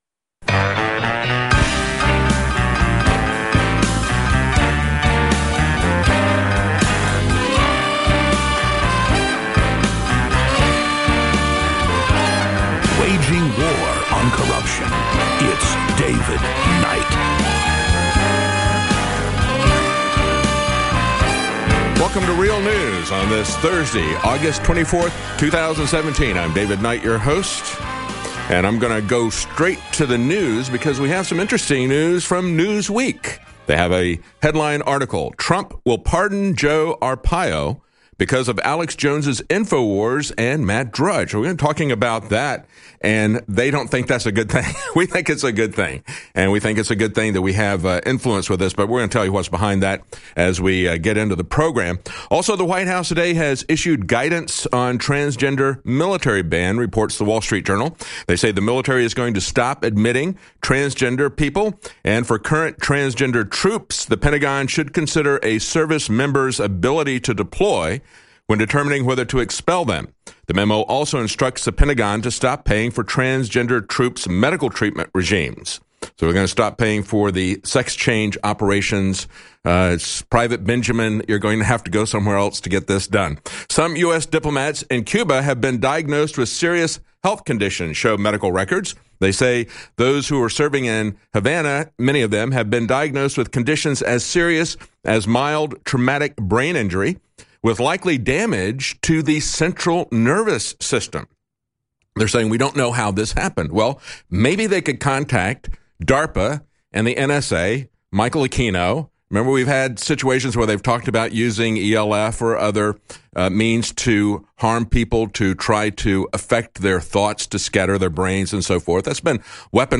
Thursday, August 24th - Trump To Pardon Sheriff Joe - At a rally on Tuesday, Donald Trump hinted that he will soon pardon Sheriff Joe Arpaio. On today's show, Roger Stone explains Trump's Afghanistan strategy and how information is being kept from POTUS.
We'll take your calls during this worldwide broadcast.